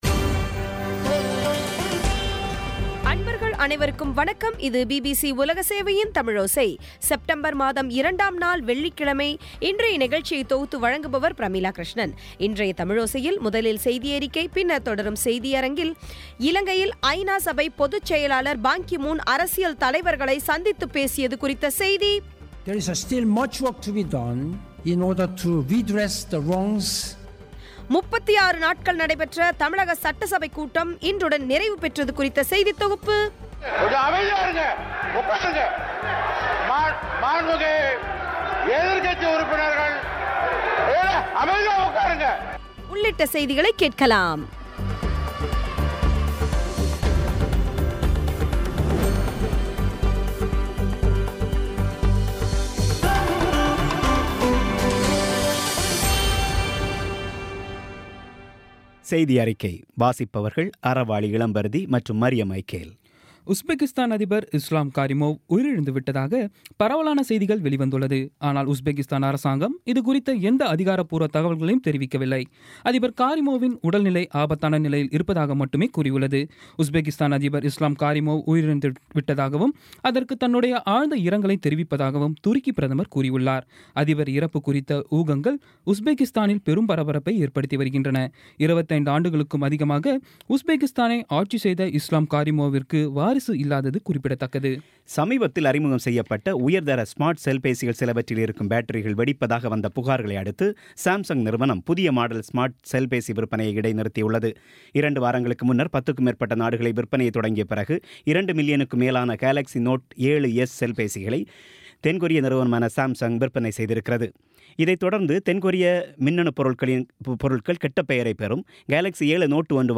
இன்றைய தமிழோசையில், முதலில் செய்தியறிக்கை பின்னர் தொடரும் செய்தியரங்கில்,